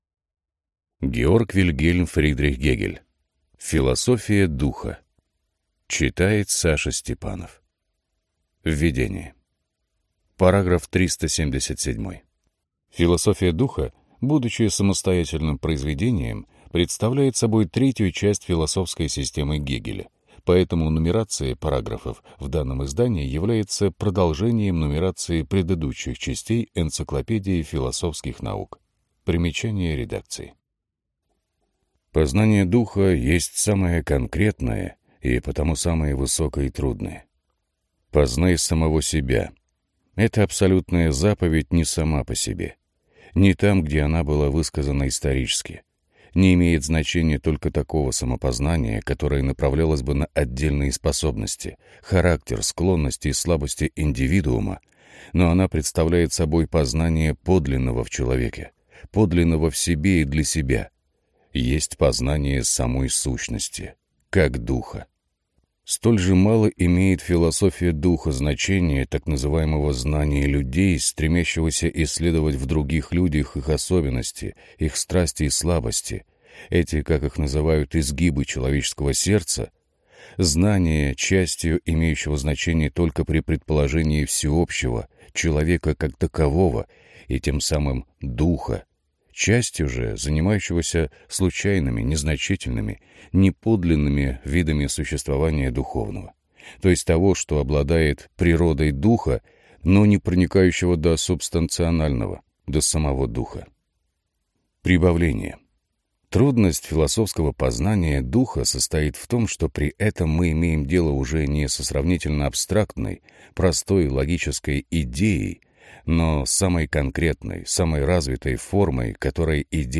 Аудиокнига Философия духа | Библиотека аудиокниг